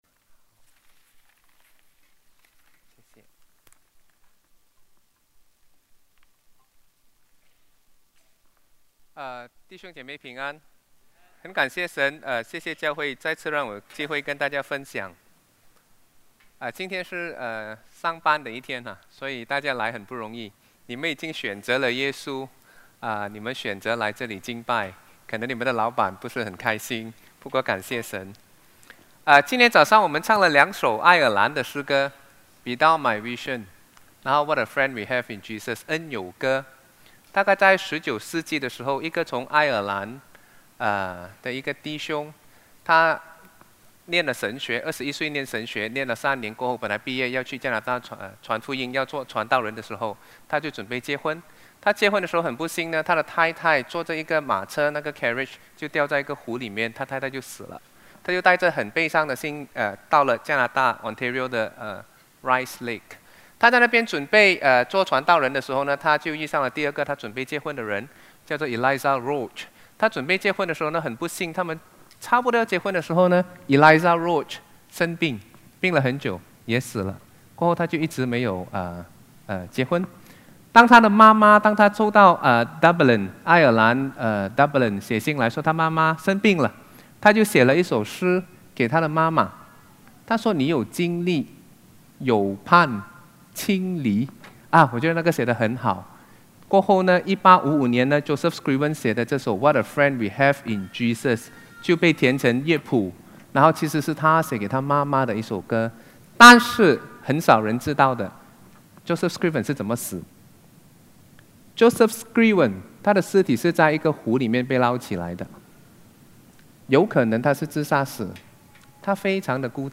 主日证道 |  不再孤单：伴我前行的灵性知己